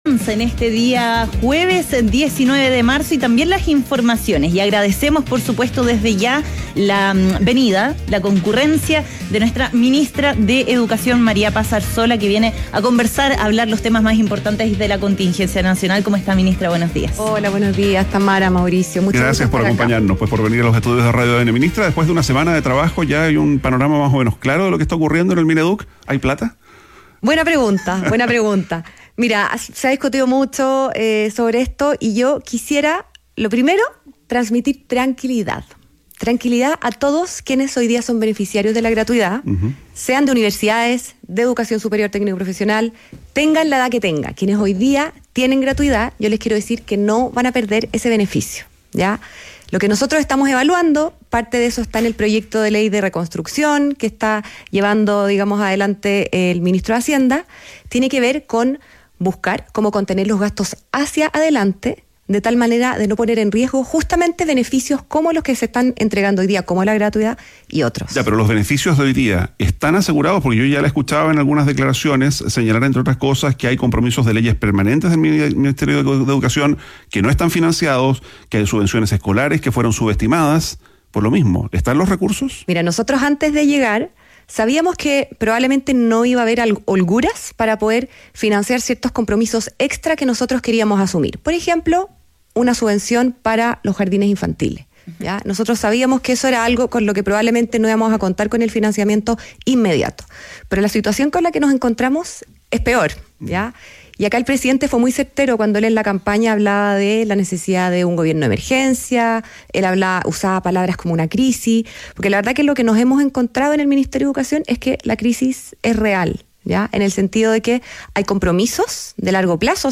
María Paz Arzola conversó con ADN Hoy sobre estos anuncios realizados por el Gobierno, que se enmarcan en el plan “Reconstrucción Nacional”, el cual busca financiar la reconstrucción de viviendas tras los incendios forestales.